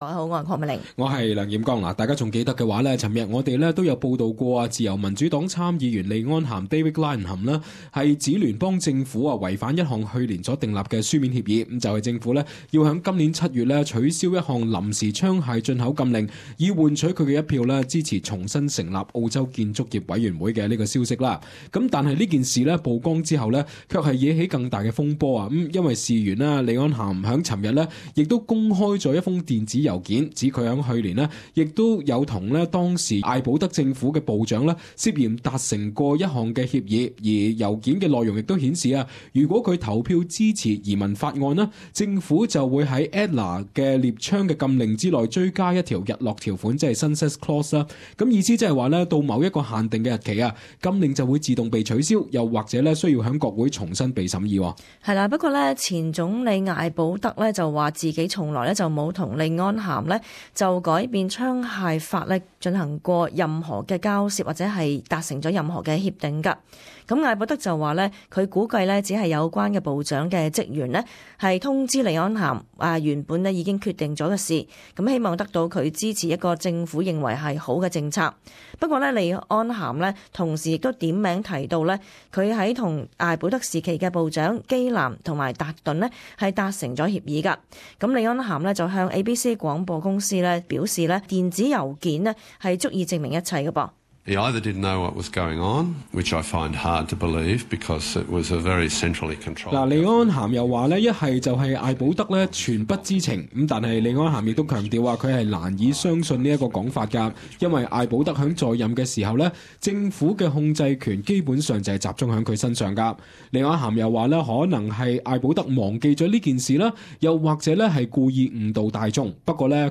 【時事報導】利安咸再爆曾與前艾保德政府部長達成協議